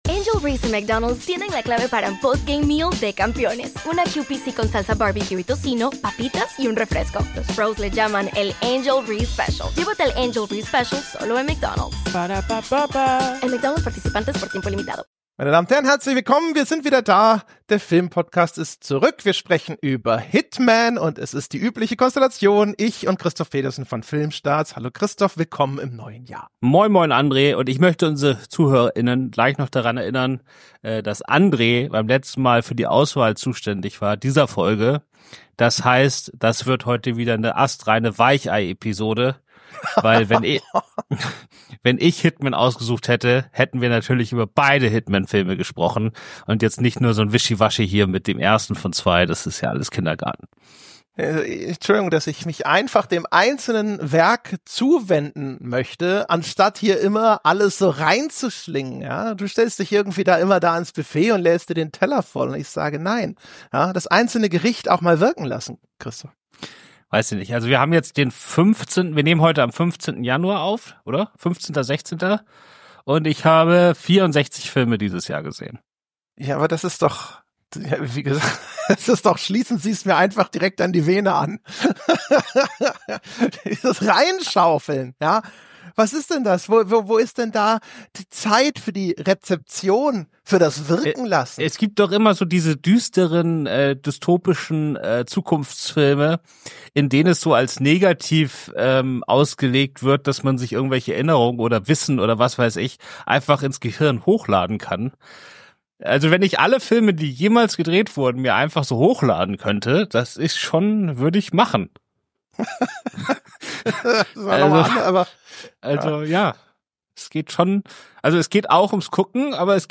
Ohne Skript, aber mit Liebe und Fachwissen.